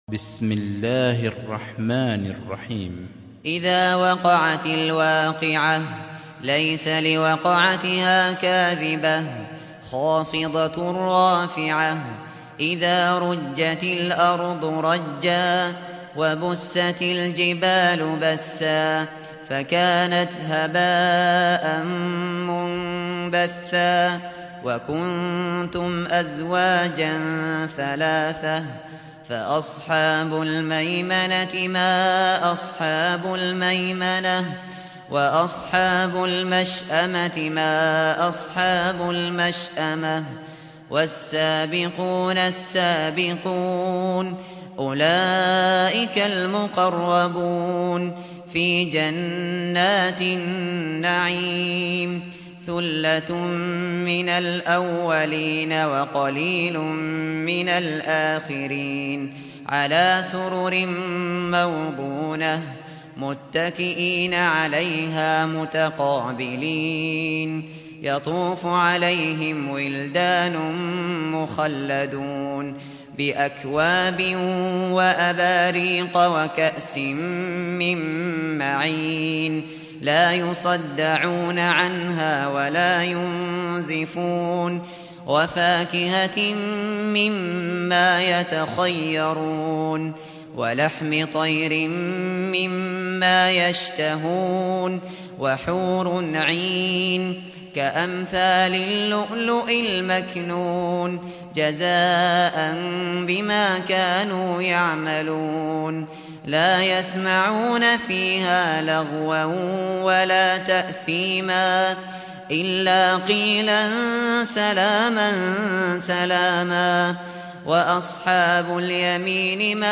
سُورَةُ الوَاقِعَةِ بصوت الشيخ ابو بكر الشاطري